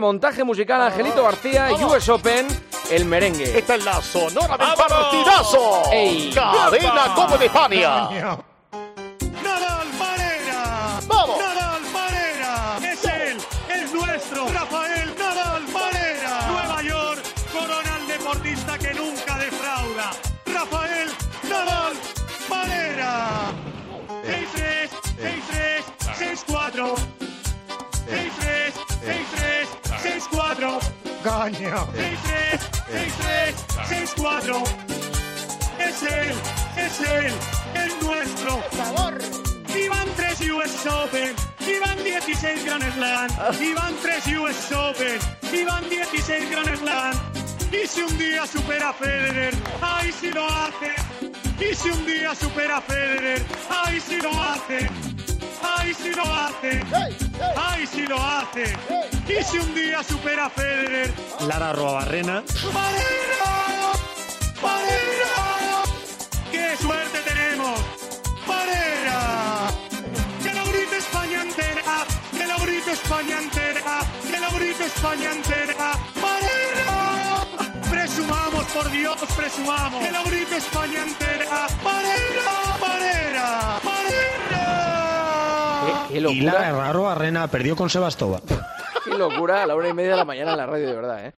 El merengue